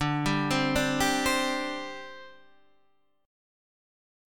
D7sus4 chord